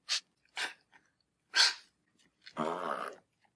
门铃响了，三只狗在叫 贵宾犬、吉娃娃、中国凤头犬的粉扑
描述：门铃响了，有三只狗在叫 贵宾犬、吉娃娃、中国冠毛犬
标签： 乱叫 奇瓦瓦州 中国 凤头 门铃 贵宾犬 粉扑 振铃
声道立体声